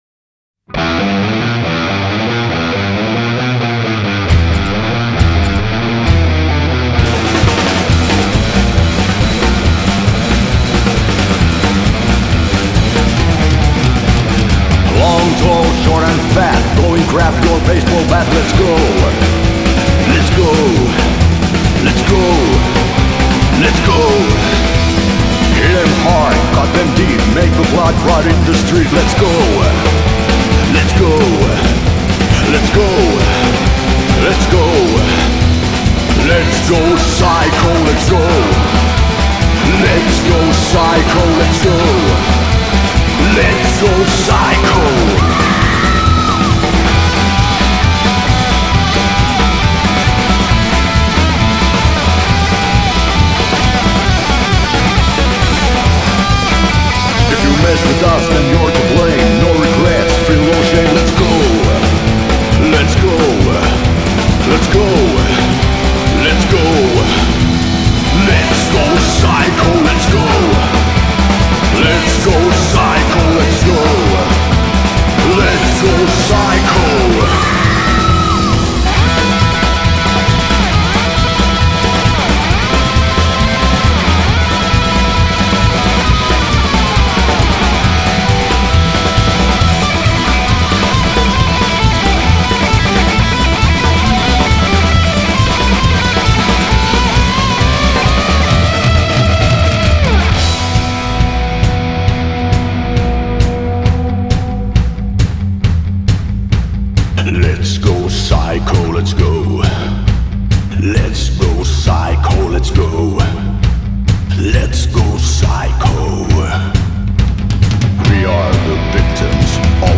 gothic-themed Rockabilly project
guitar
drummer